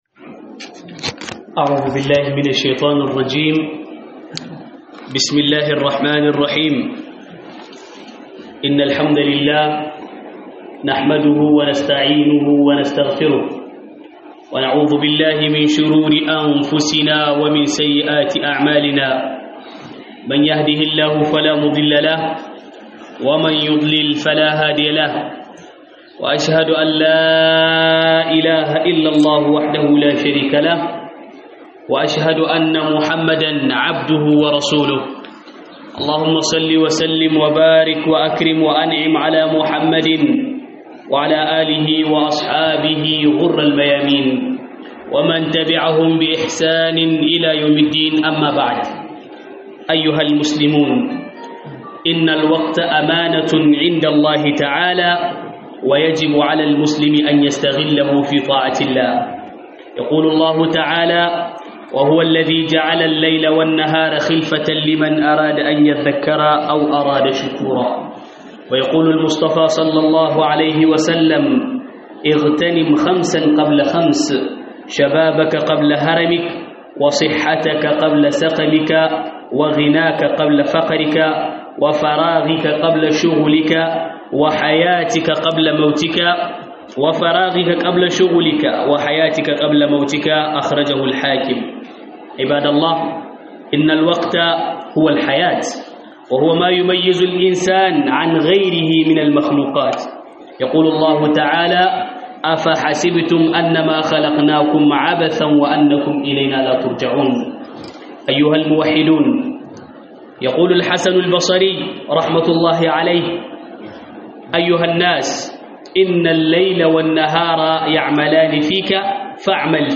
Huɗuba akan mahimmancin lokaci